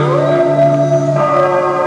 Howl Sound Effect
Download a high-quality howl sound effect.
howl.mp3